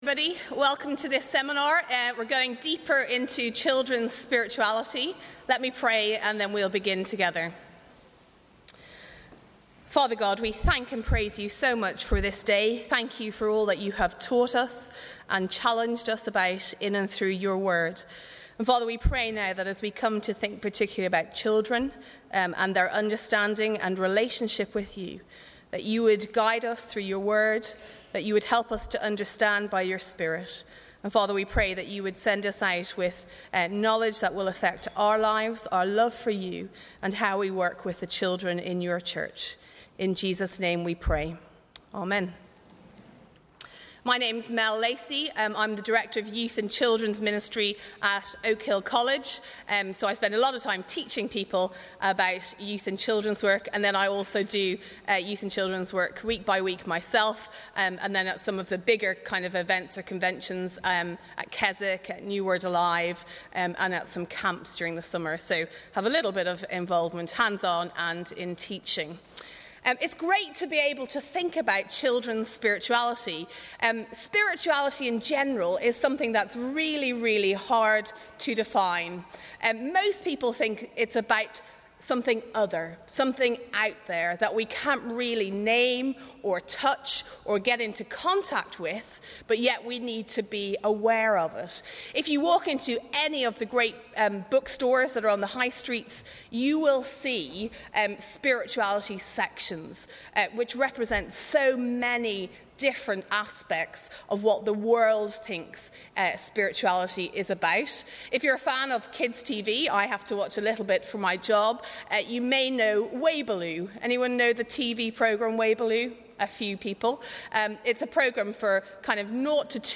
Talks and seminar recordings from Growing Young Disciples in Southampton (2011), London (2011) and Rugby (2012).
(Please note that the seminar and workshop recordings have not been edited throughout, so there may be some pauses where group discussions have taken place)